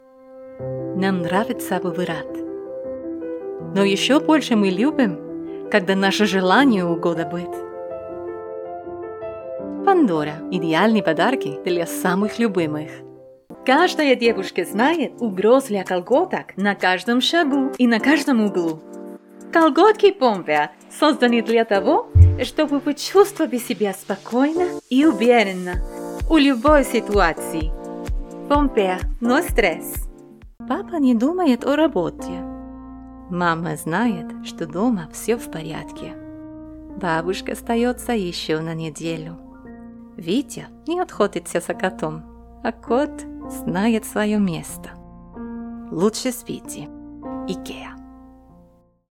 Female
Television Spots
Russian: Elegant, Soft, Clear
0902Russian_Commercial_Reel.mp3